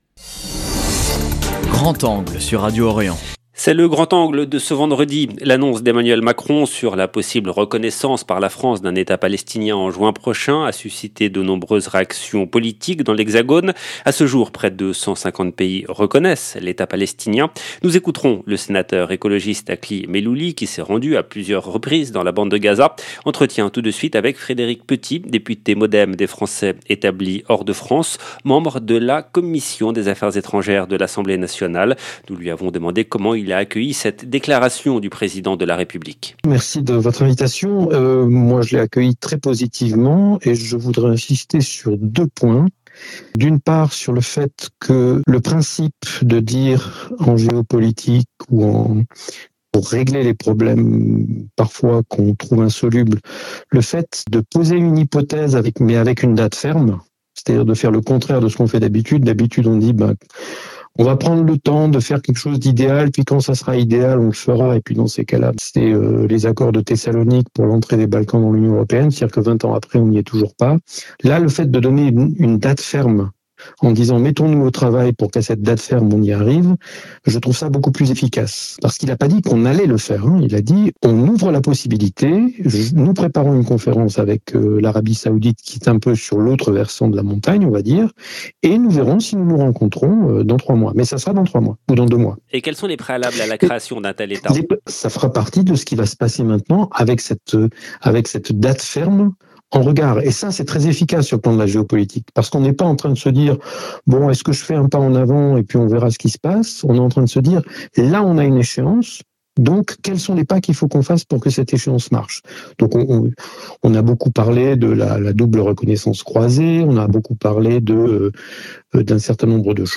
Nous écouterons le sénateur écologiste Akli Mellouli. Entretien tout de suite avec Frédéric Petit, député Modem des Français établis hors de France, membre de la Commission des affaires étrangères de l’Assemblée nationale. 0:00 12 min 31 sec